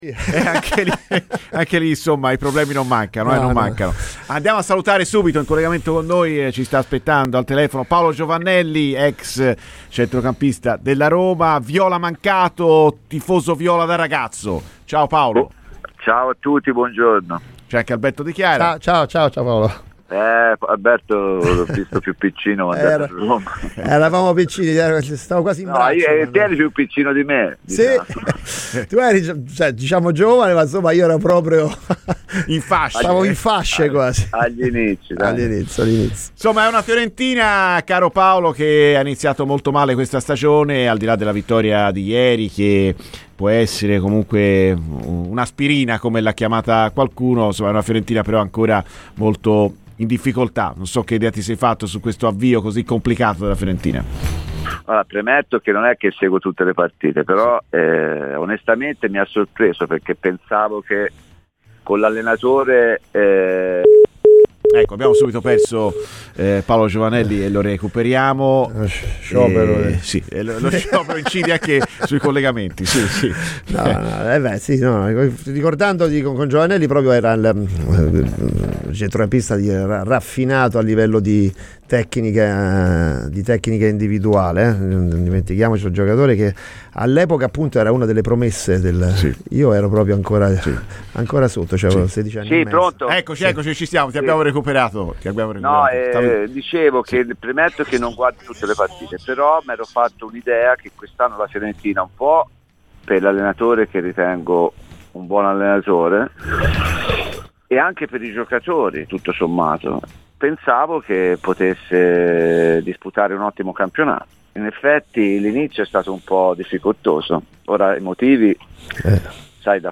ex Roma dal 1977 al 1983 e tifoso viola da ragazzo, è intervenuto ai microfoni di RadioFirenzeViola durante la trasmissione "Viola Amore Mio" a proposito dell'inizio di stagione della squadra di mister Pioli